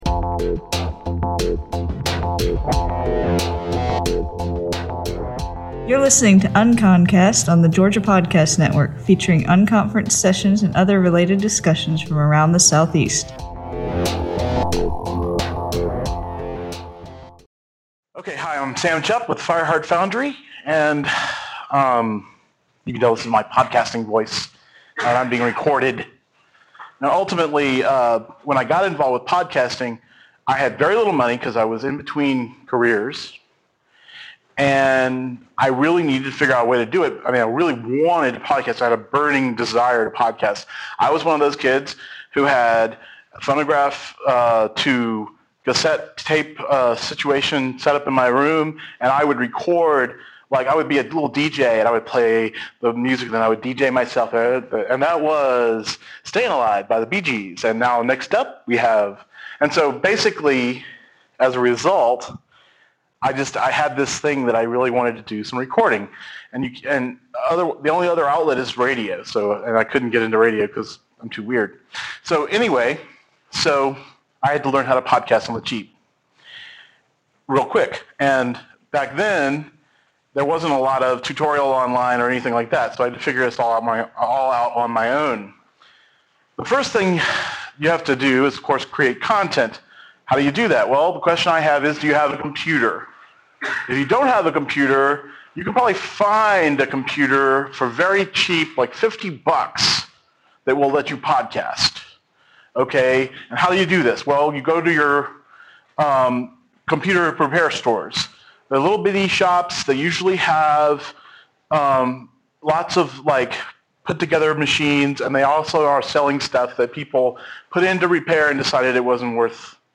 PodCamp Atlanta was an unconference which took place March 17 and 18 at Emory University.